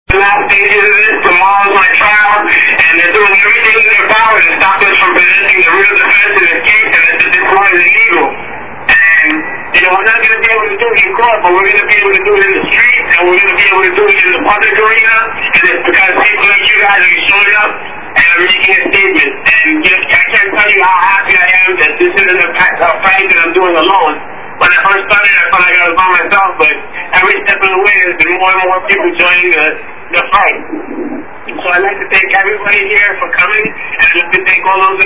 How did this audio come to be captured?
Holding Phone To Mic Speaking Over The Phone Speaking Via Cellphone